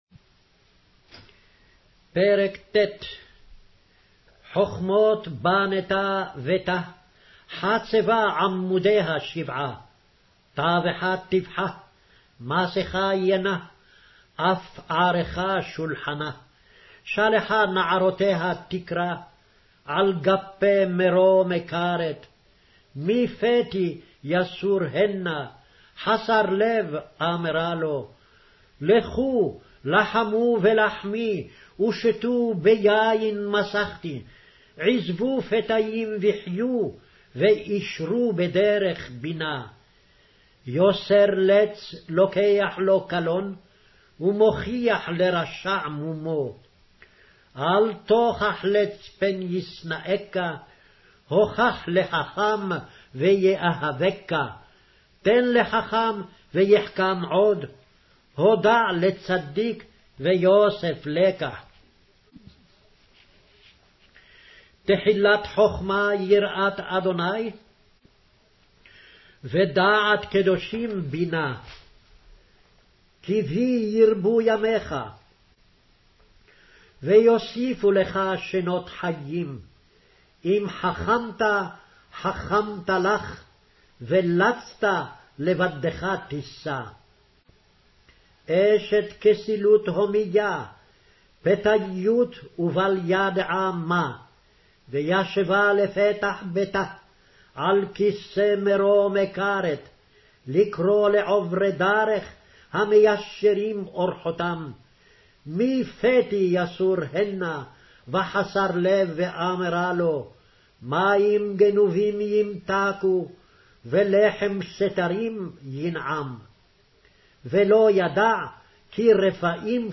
Hebrew Audio Bible - Proverbs 5 in Ervbn bible version